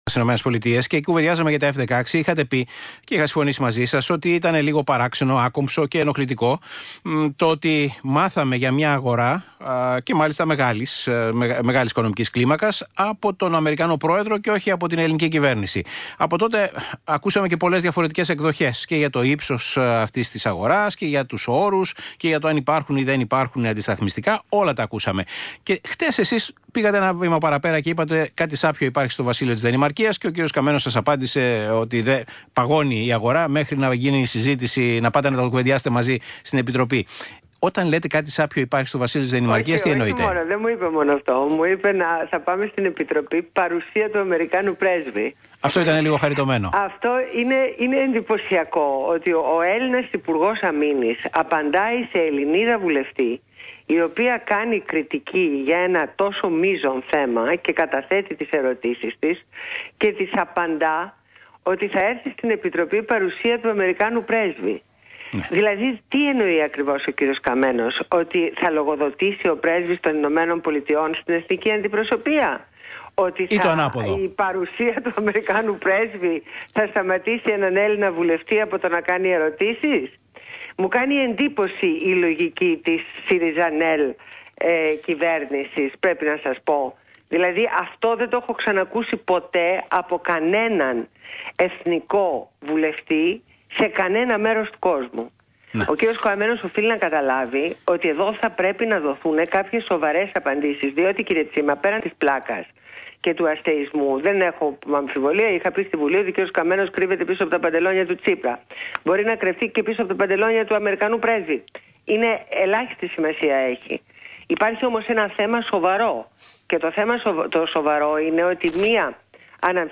Συνέντευξη στο ραδιόφωνο του ΣΚΑΙ